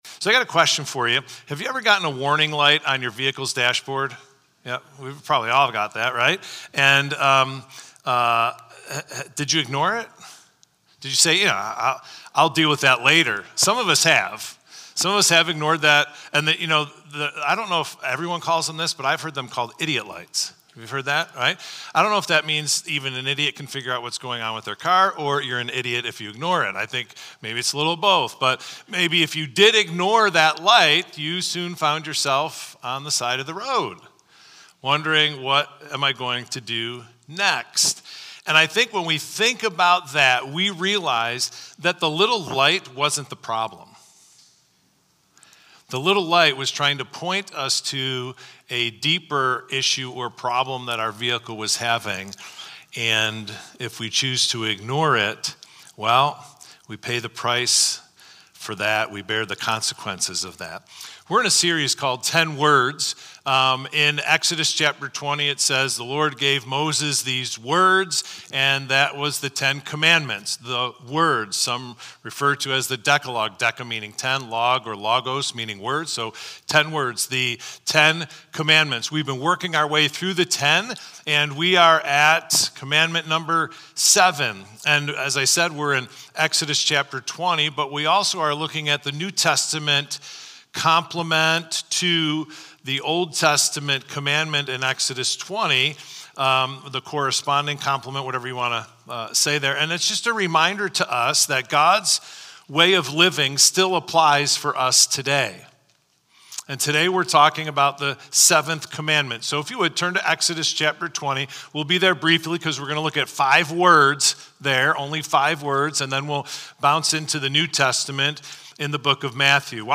Victor Community Church Sunday Messages / 10 Words: God Cares About Your Faithfulness (August 24th, 2025)